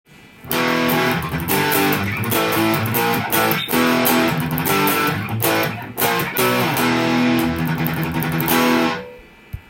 フライングVを弾いてみました
低音弦をズクズクさせて弾いていました。硬めのしっかりした音がします。